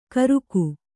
♪ karuku